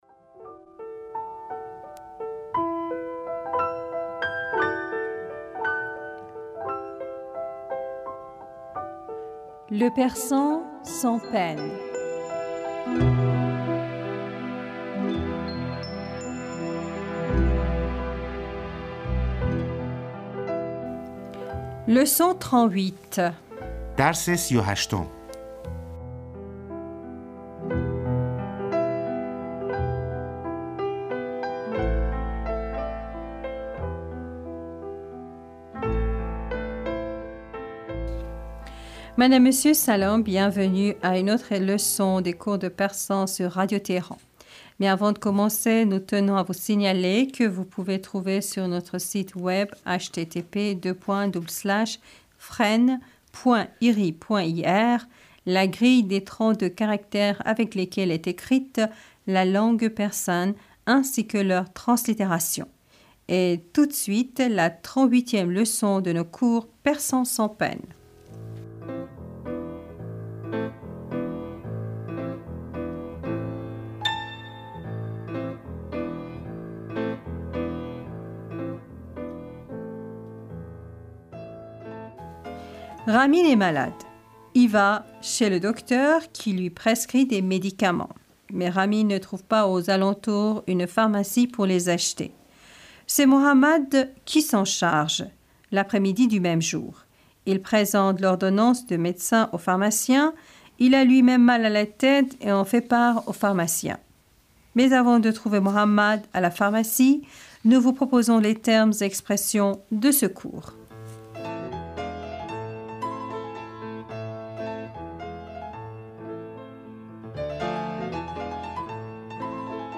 Bienvenus à une autre leçon des cours de persan sur Radio Téhéran.